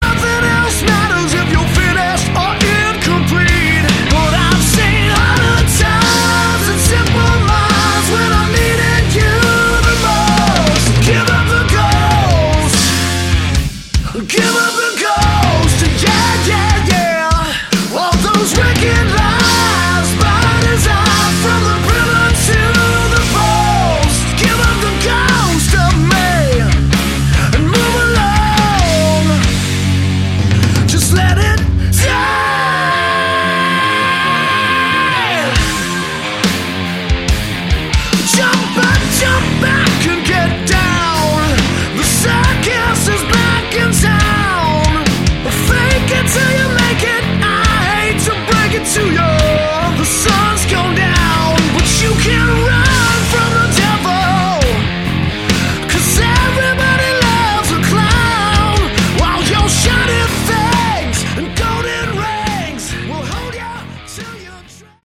Category: Hard Rock
vocals
drums
bass
guitar